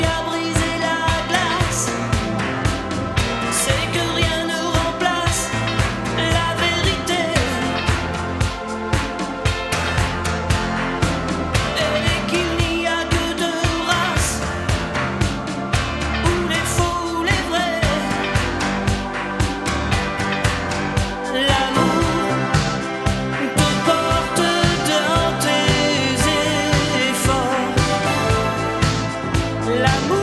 "templateExpression" => "Chanson francophone"